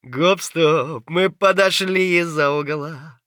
scav3_mutter_30.wav